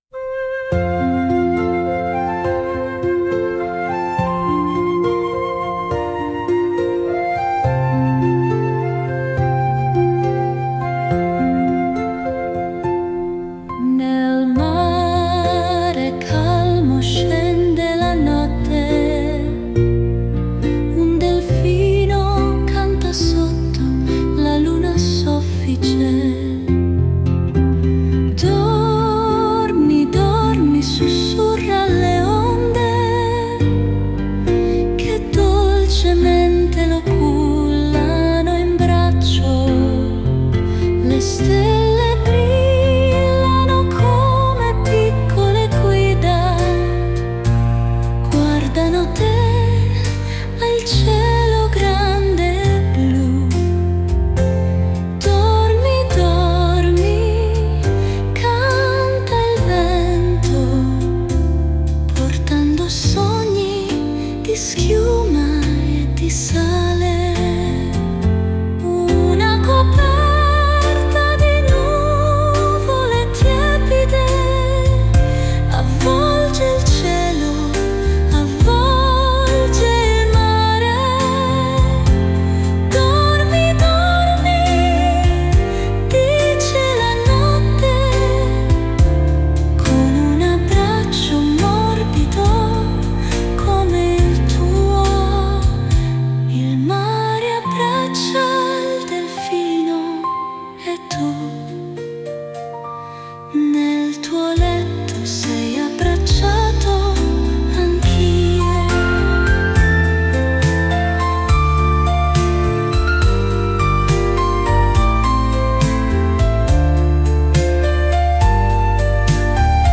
Ninna-Nanna-del-delfino.mp3